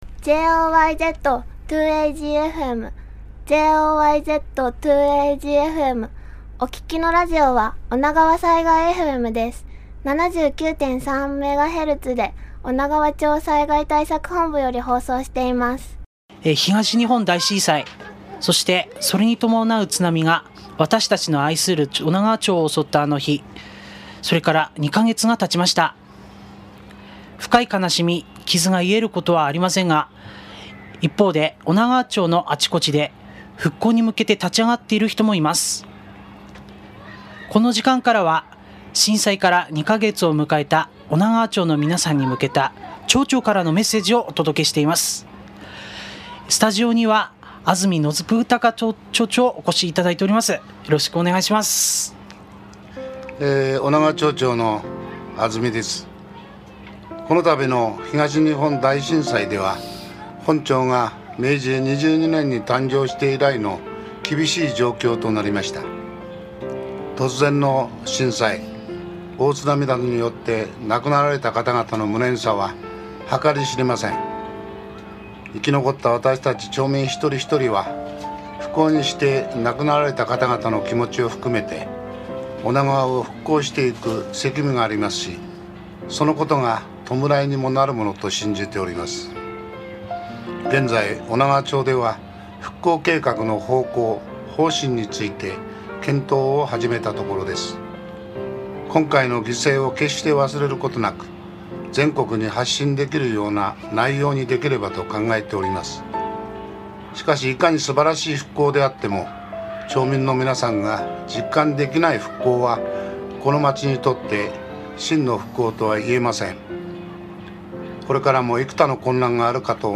現時点での町としての考えや、状況についてなどを 女川町長 安住宣孝からのメッセージを ５月１２日に収録し、放送しました。
azumi_voice.mp3